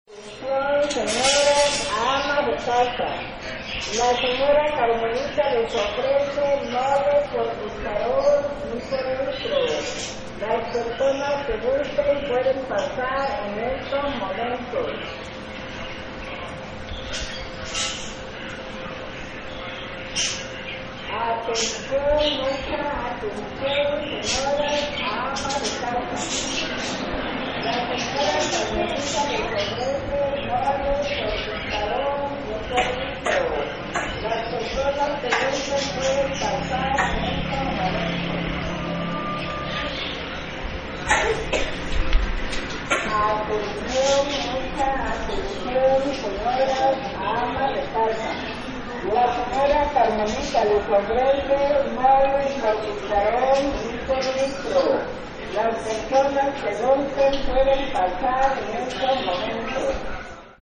Es sorprendente que aún en ciertos lugares de las ciudades se conserve el uso de los megáfonos que anuncian la venta de comida o productos, es por eso que presentamos este audio que fue realizado en una colonia ubicada en el lado oriente norte de la ciudad de Tuxtla Gutierrez, Chiapas.